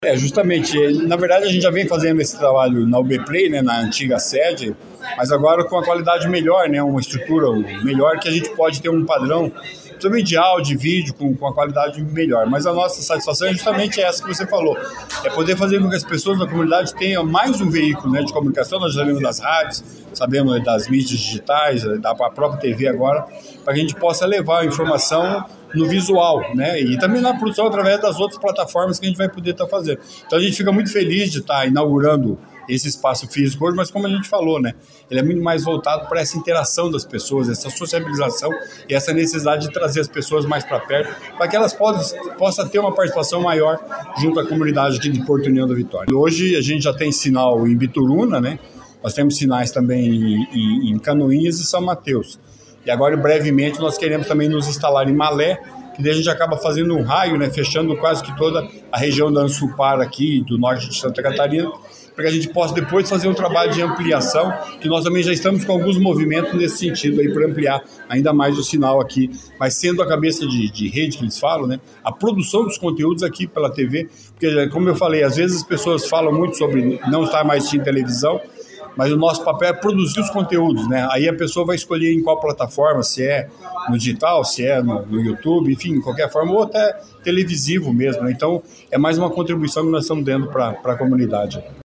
Na noite desta quinta-feira (14), a TV UBPLAY inaugurou oficialmente seu novo e moderno prédio em União da Vitória.